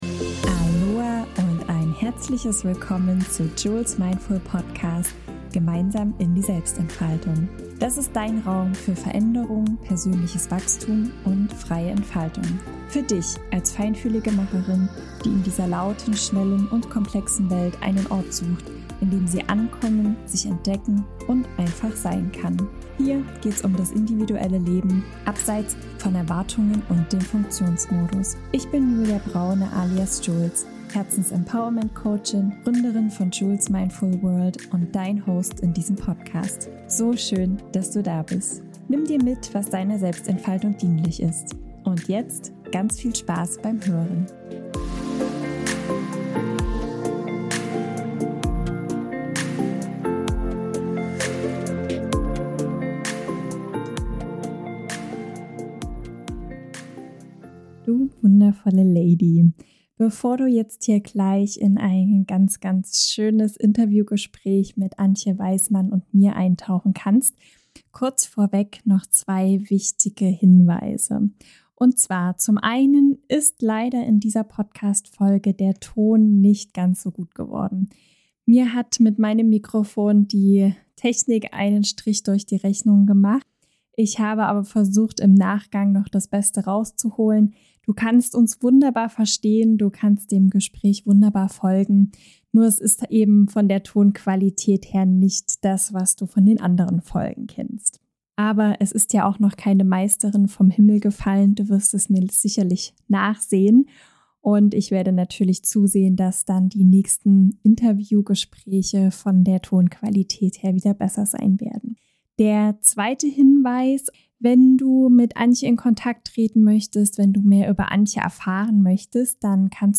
Viel Freude beim Hören und lass uns so gerne wissen, wie dir dieses erste Interviewgespräch gefallen hat.